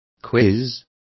Complete with pronunciation of the translation of quizzing.